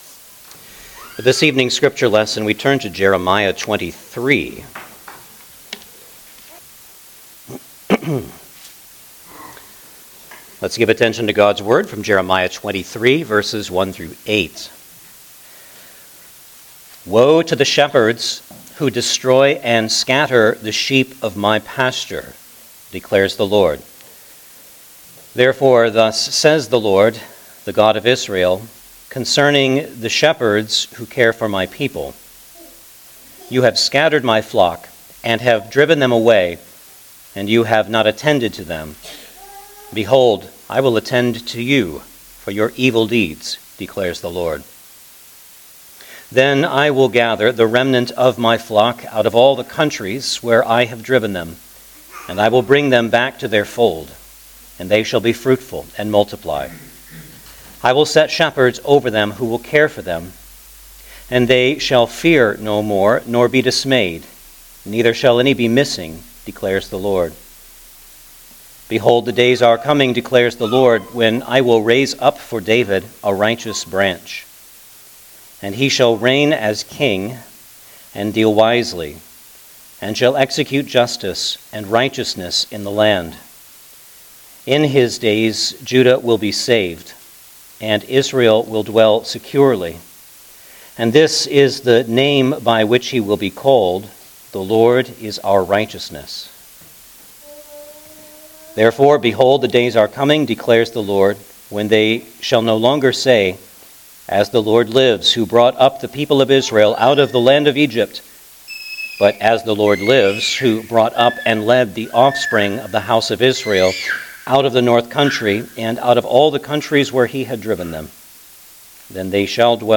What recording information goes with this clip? Jeremiah Passage: Jeremiah 23:1-8 Service Type: Sunday Evening Service Download the order of worship here .